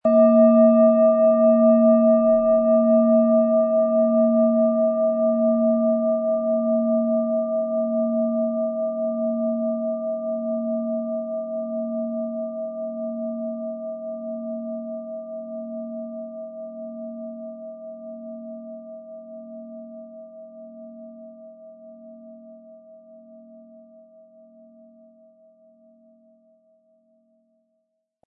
Planetenton
Alphawelle
Von Hand getriebene Klangschale mit dem Planetenklang Alphawelle aus einer kleinen traditionellen Manufaktur.
Im Sound-Player - Jetzt reinhören hören Sie den Original-Ton dieser Schale. Wir haben versucht den Ton so authentisch wie machbar hörbar zu machen, damit Sie hören können, wie die Klangschale bei Ihnen klingen wird.
Im Lieferumfang enthalten ist ein Schlegel, der die Schale wohlklingend und harmonisch zum Klingen und Schwingen bringt.
MaterialBronze